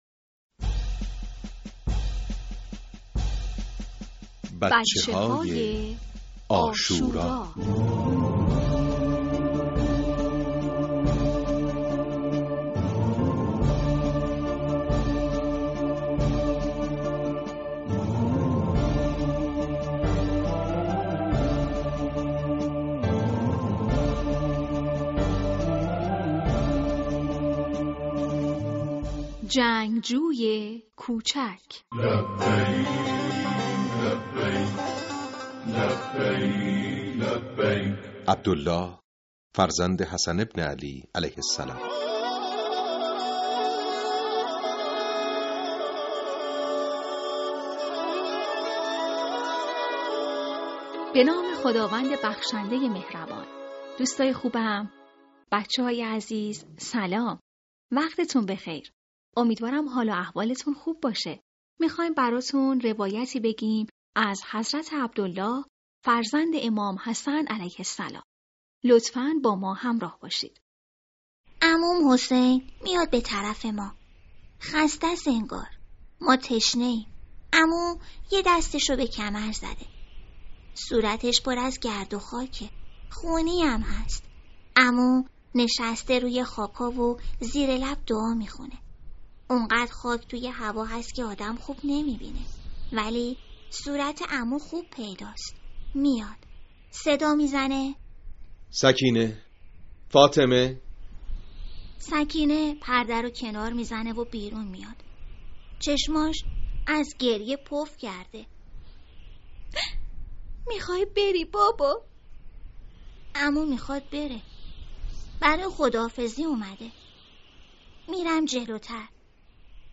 صوتی | بچه‌های عاشورا (02) دانلود صوت بفرمایید قصه بچه‌های عاشورا "جنگجوی کوچک" این قسمت: عبدالله فرزند حسن ابن علی علیه‌السلام این قصه رو همراه با فرزندتون بشنوید. # بچه های عاشورا # حضرت عبدالله ابن الحسن علیه السلام # قصه # ماه محرم الحرام # پادکست # کتاب صوتی